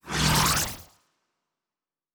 pgs/Assets/Audio/Sci-Fi Sounds/Doors and Portals/Teleport 1_1.wav at master
Teleport 1_1.wav